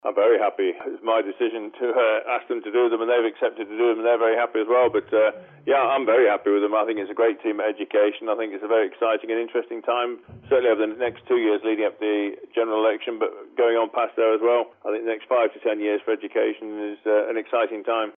Minister Tim Crookall says the department is happy with its lot: